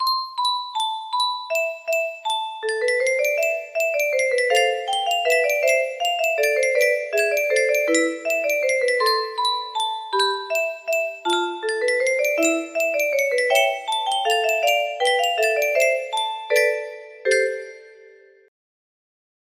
Minuet in G Minor - Christian Petzold (1st half) music box melody
A take on theminuet, adapted for a music box.